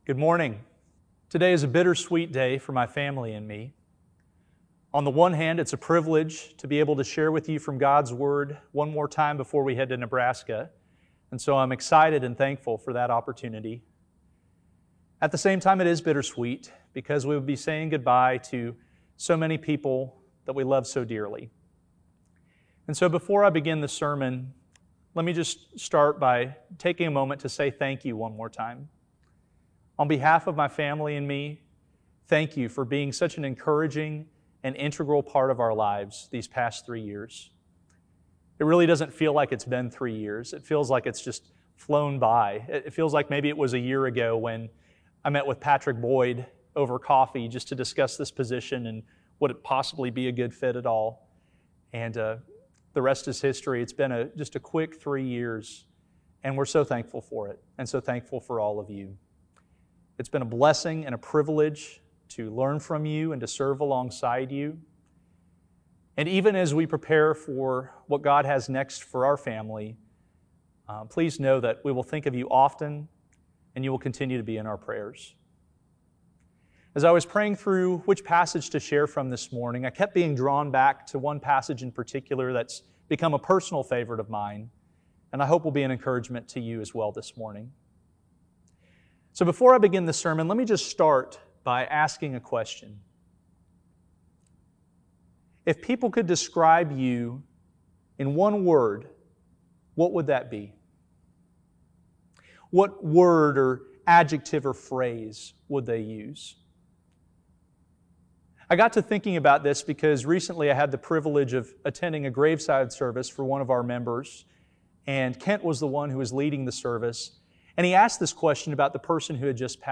Discussion questions for this sermon.